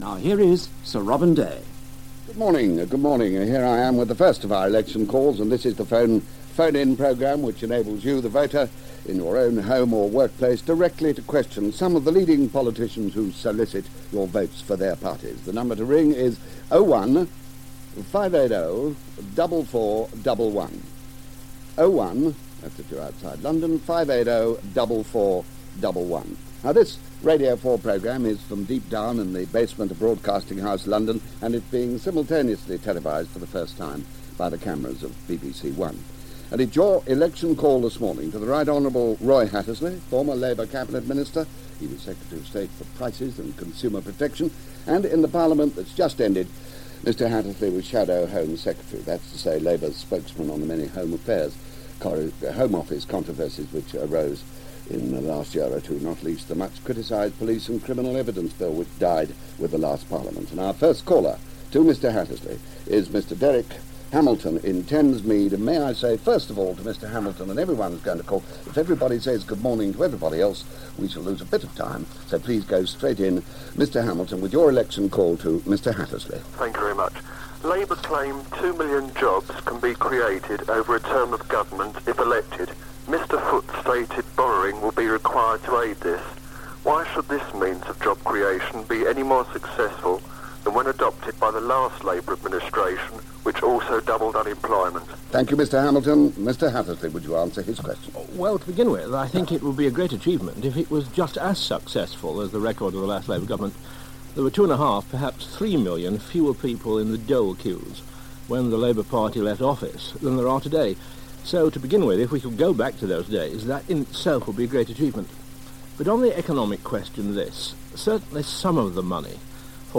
Back in 1983 members of the public could put their questions directly to MPs during General Election campaigns on BBC Radio 4's Election Call. Hosting this for a few years was Robin Day. In this edition (extract only) from 16 May 1983 questions are put to Labour's Roy Hattersley.